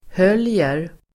Uttal: [h'öl:jer]